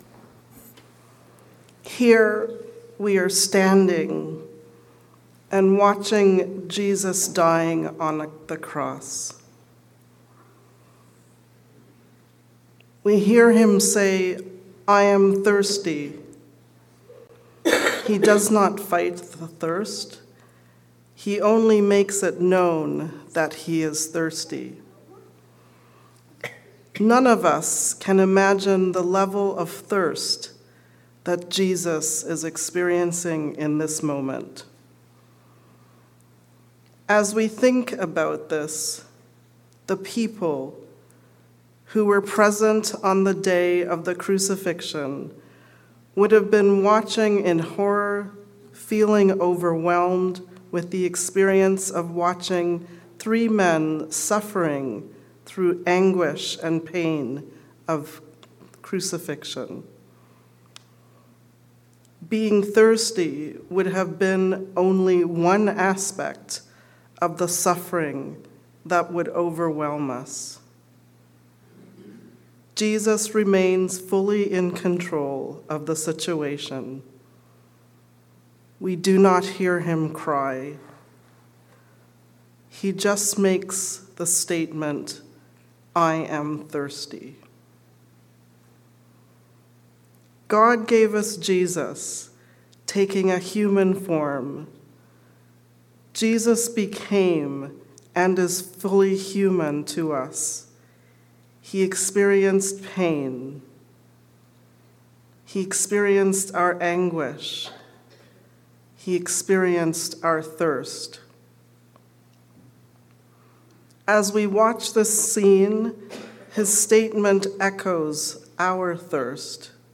I thirst. A sermon for Good Friday